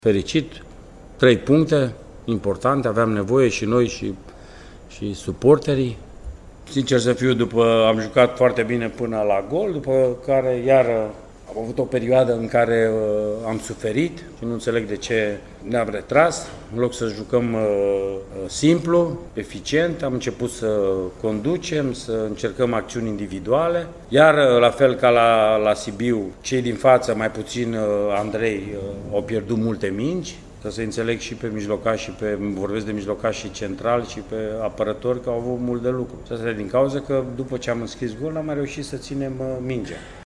La finalul meciului, antrenorul Mircea Rednic s-a declarat mulțumit doar de cele trei puncte: